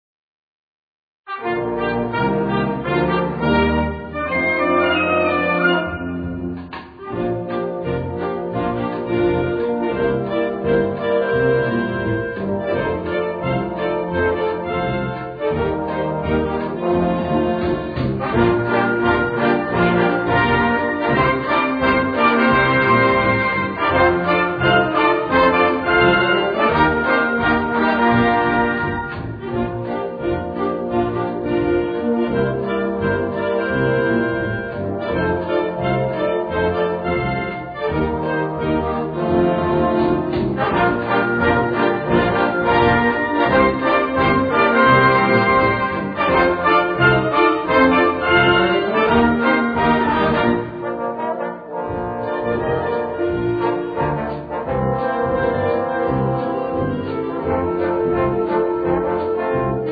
Gattung: Evergreen
Besetzung: Blasorchester